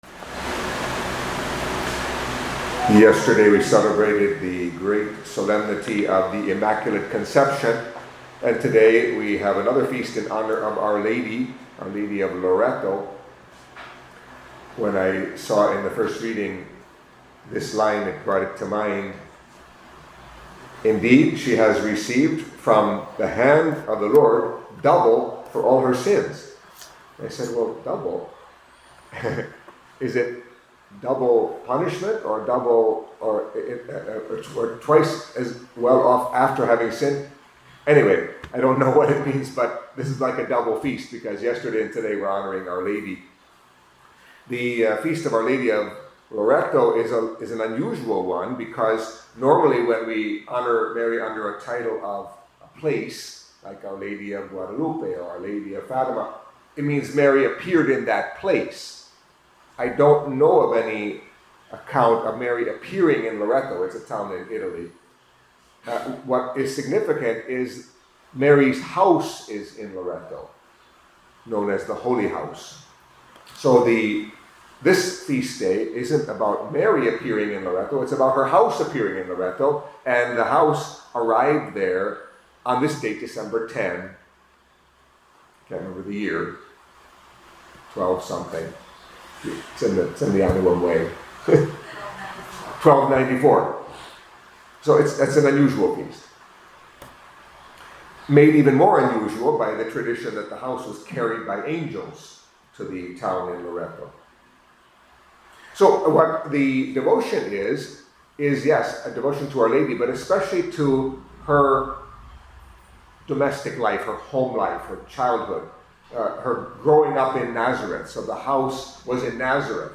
Catholic Mass homily for Tuesday of the Second Week of Advent